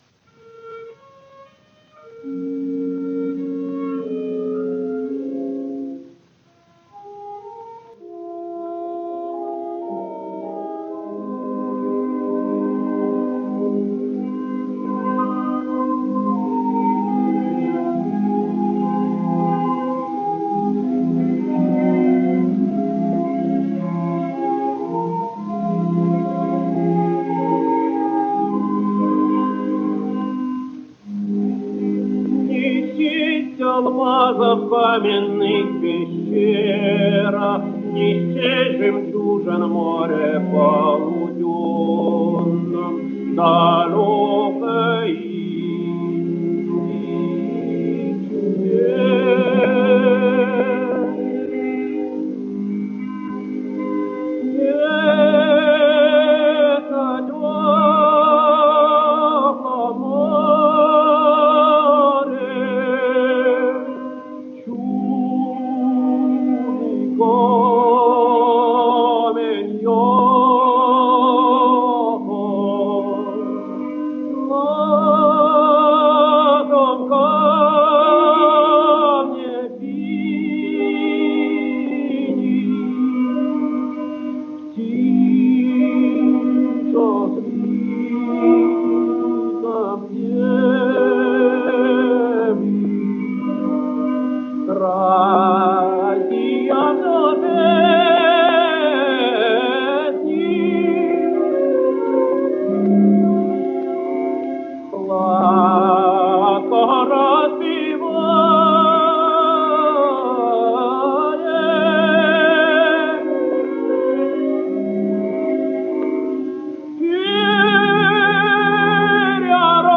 Belarusian Tenor